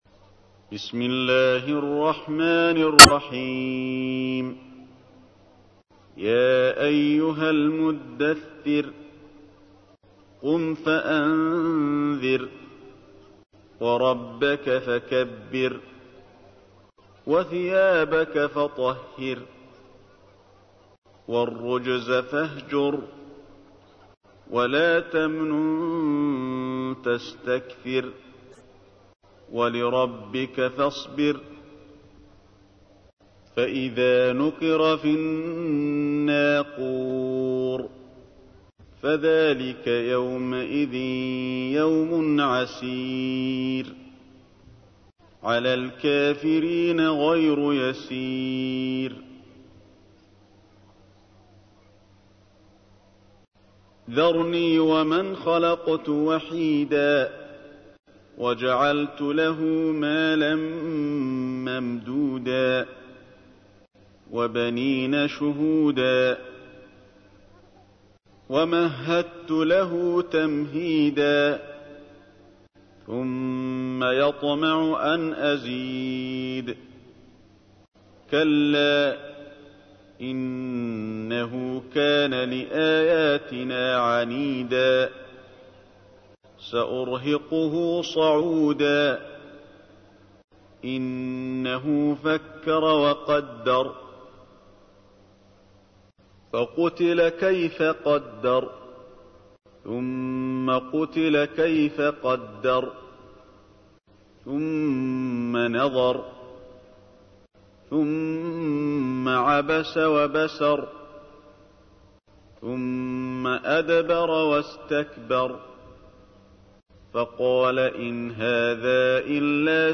تحميل : 74. سورة المدثر / القارئ علي الحذيفي / القرآن الكريم / موقع يا حسين